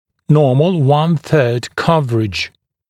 [‘nɔːml wʌn θɜːd ‘kʌvərɪʤ][‘но:мл уан сё:д ‘кавэридж]нормальное перекрытие на одну треть (о резцовом перекрытии)